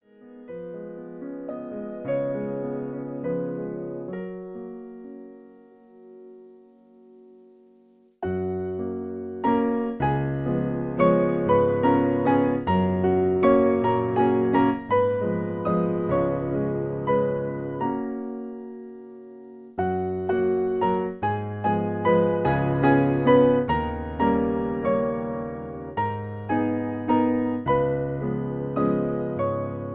Berühmtes Schweizer Volkslied